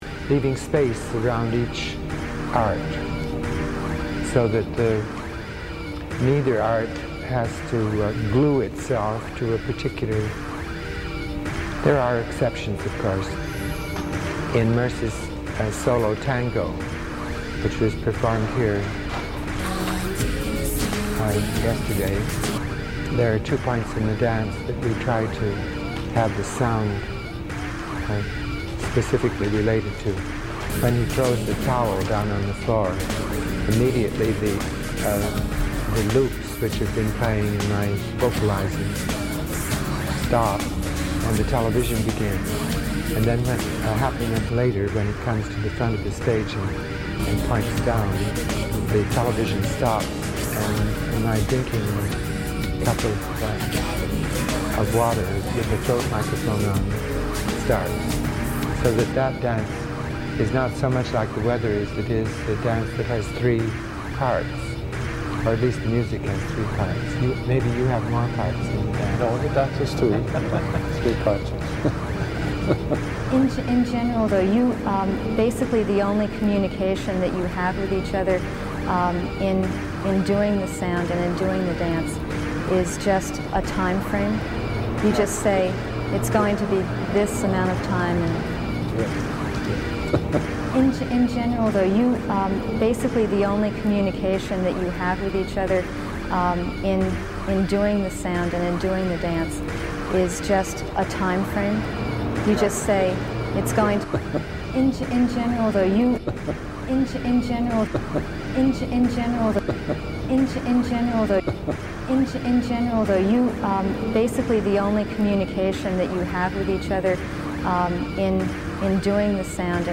Part two of the premiere broadcast of this new weekly show on Wave Farm Radio and WGXC. In a stew of intimate electronics, bad poetry, and tender murk, we swap tongues and reach for more.